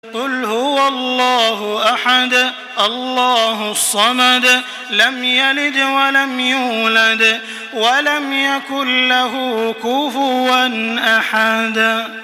Surah Al-Ikhlas MP3 by Makkah Taraweeh 1425 in Hafs An Asim narration.
Murattal